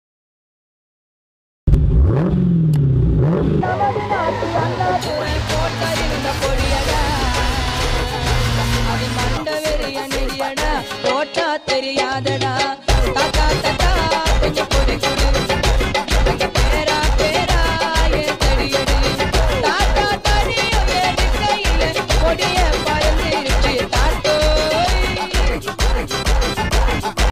Tamil Ringtones